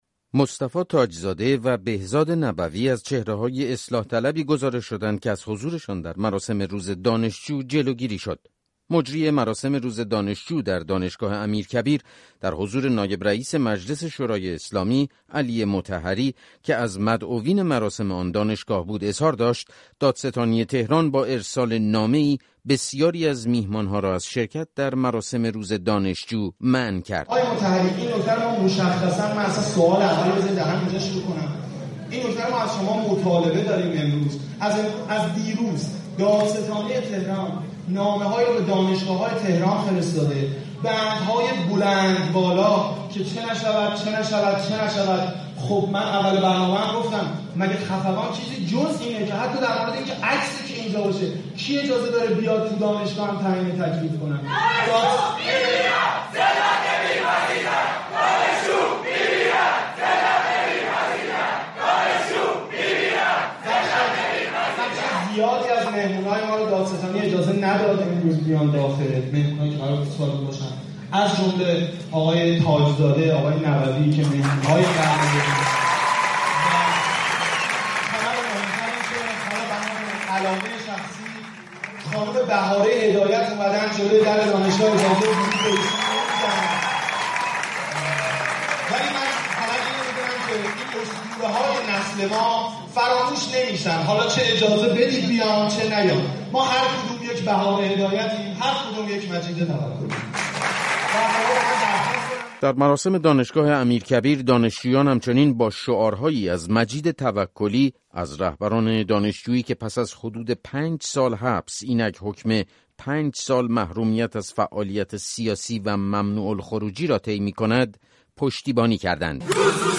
گزارش‌های رادیویی